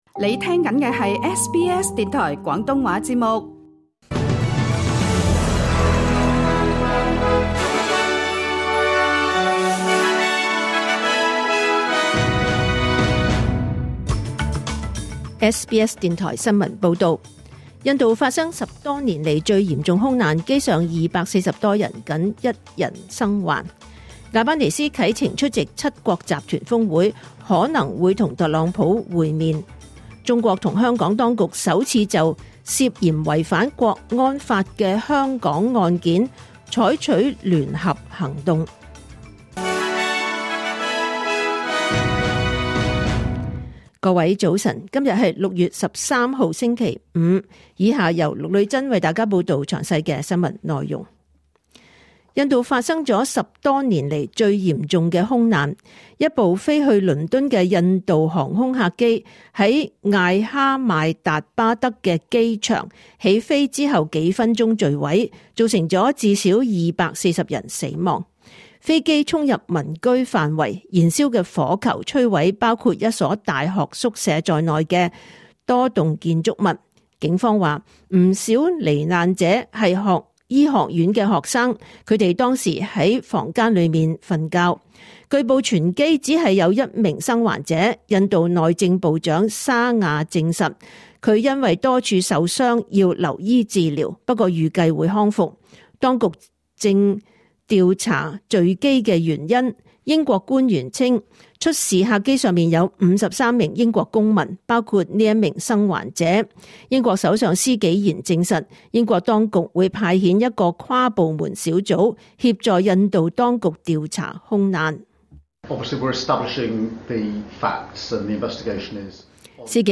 2025年6月13日SBS廣東話節目九點半新聞報道。